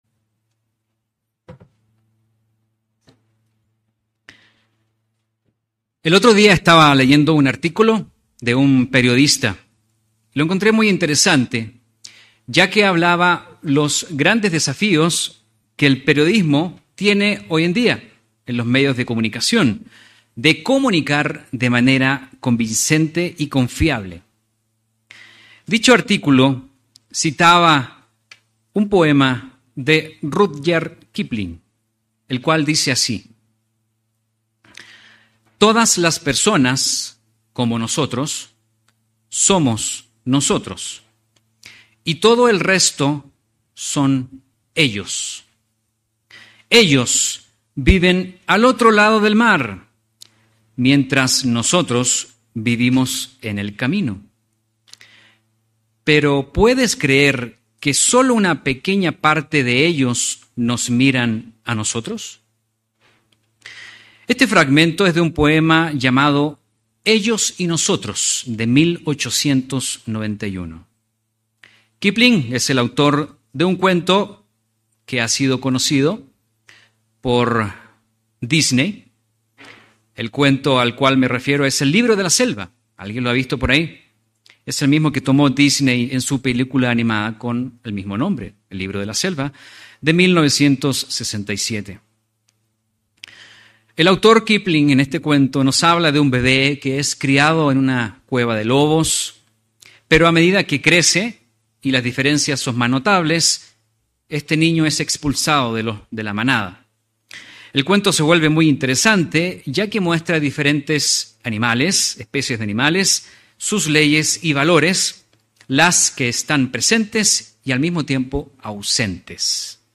El precioso llamamiento de Dios nos abre el entendimiento al proceso de salvación de toda la humanidad; una salvación que no es para unos pocos. Mensaje entregado el 9 de diciembre de 2023.